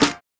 Snare (Love).wav